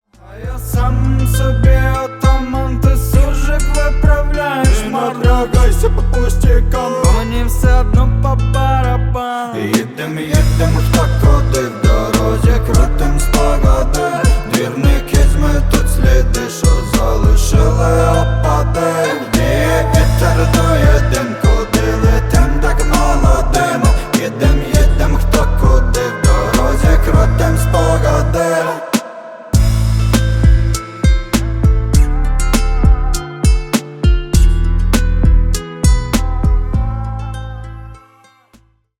• Качество: 320 kbps, Stereo
Рэп и Хип Хоп